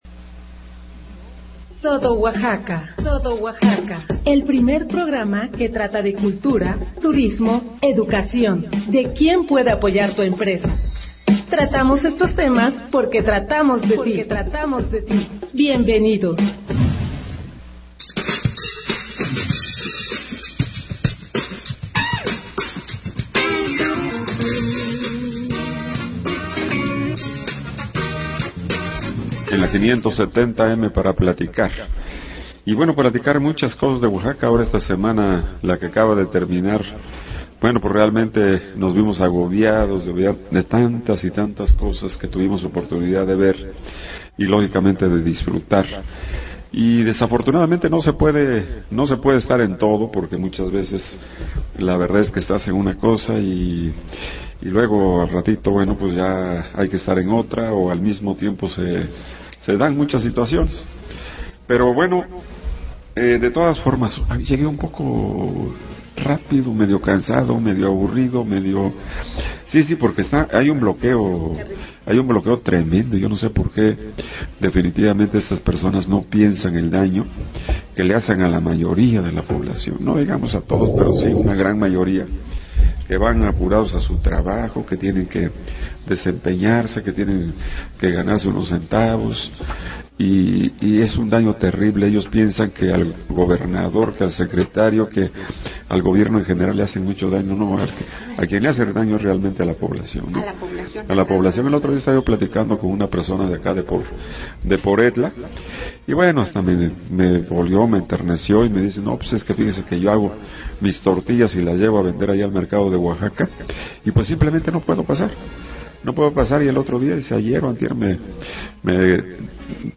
Escucha la emisión del 24 de julio